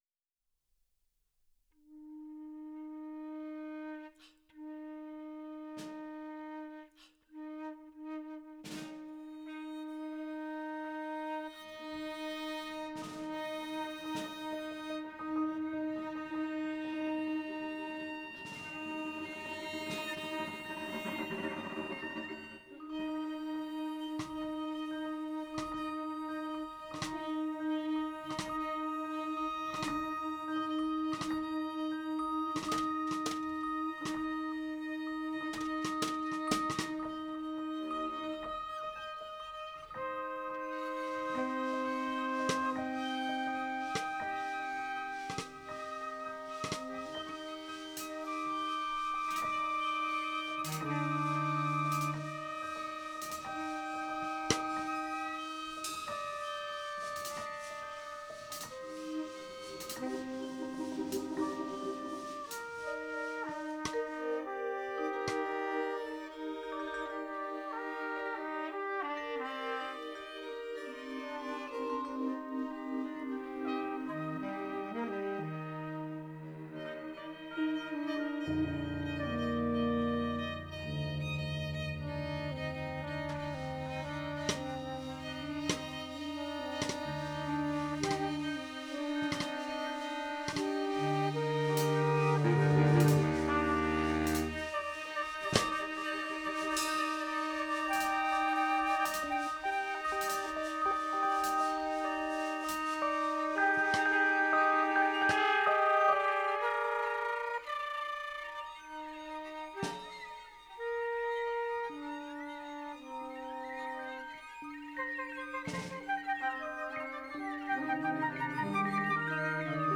live electronics, spectralist compositional techniques, and a focus on texture and sound design, often blending dance, ambient, and contemporary classical styles
Stresshead-Fringe-Science-ensemble-version.wav